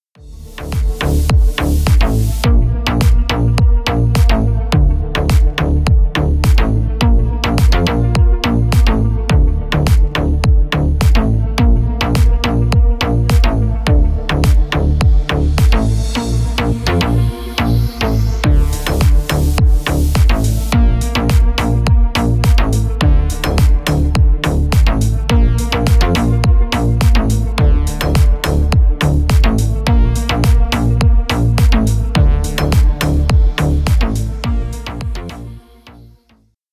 Танцевальные
клубные # без слов